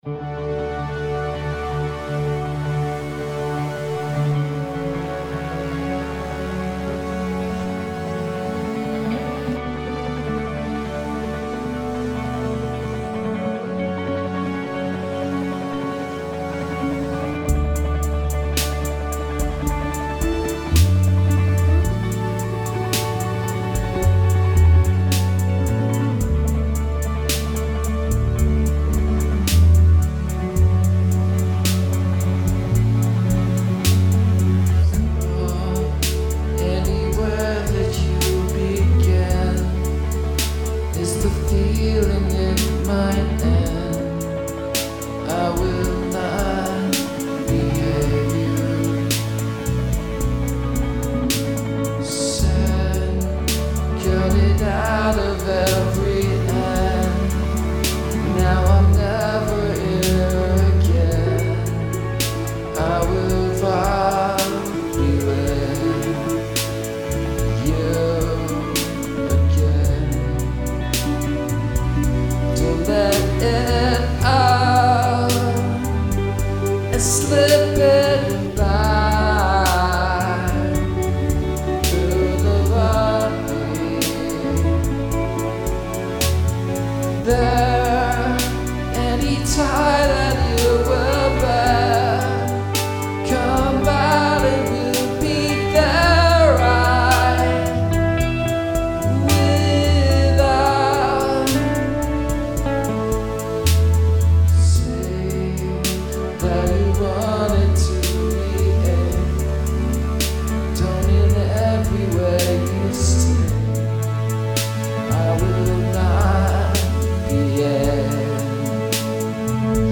I started writing the song on the ukulele, writing the melody first.
Of course, I do a lot of glorious cliches in this song, especially my extra tasteful solo near the end.
I wanted the song to sound like a slow exhalation of breath.
Man if you say "tasteful" with derision you must hate a lot of my solos I liked the pad and the tremelo guitar thing.
I was going to actually record live drums for this (when I'm doing these songs I record my parts to a drum loop much like this and then go back and add the drums) but I decided against it, mainly because I was running out of time and also because I sort of like the flat dynamic of the rhythm.